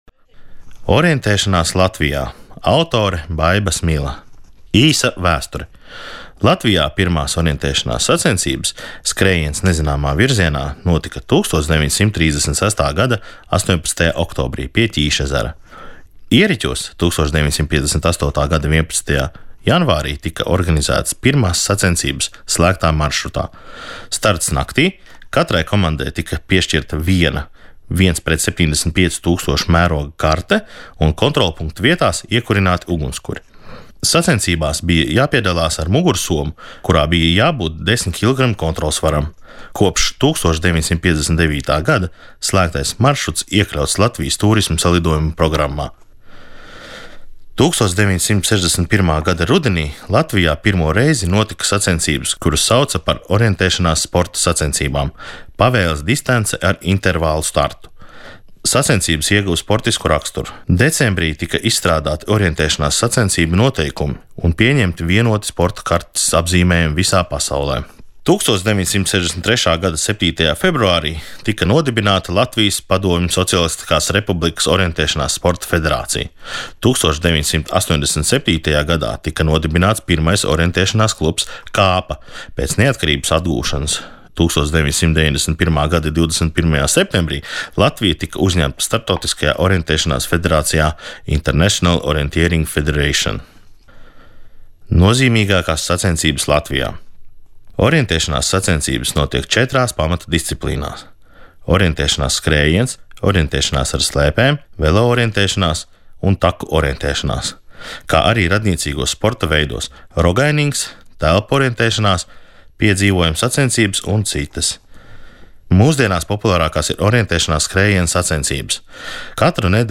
2019. gadā, atzīmējot Nacionālās enciklopēdijas drukātā sējuma "Latvija" (2018) pirmo gadadienu, Latvijas Nacionālās bibliotēkas un Latvijas Radio darbinieki sagatavoja Nacionālās enciklopēdijas drukātā sējuma šķirkļu audio ierakstus.